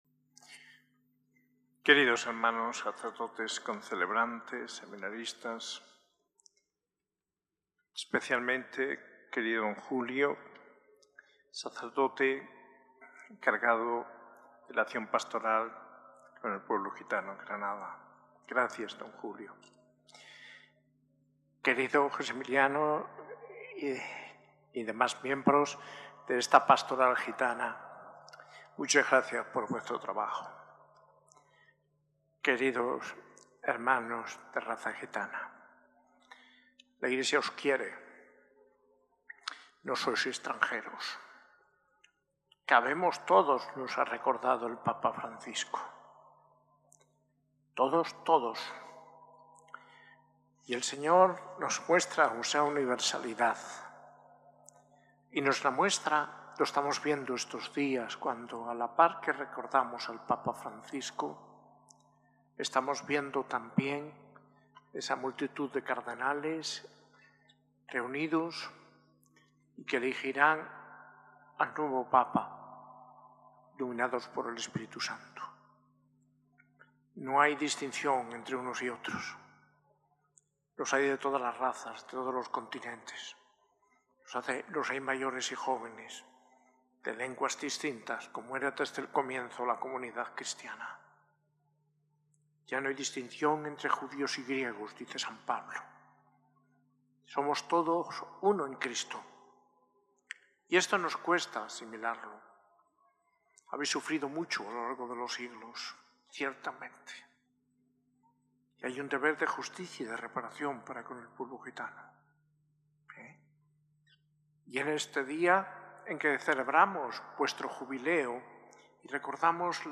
Homilía del arzobispo de Granada, Mons. José María Gil Tamayo, en la Eucaristía en el jubileo de la Pastoral Gitana, el III Domingo del Tiempo Pascual, el 4 de mayo de 2025, en la S.A.I Catedral.